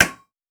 Ball Hit Helmet.wav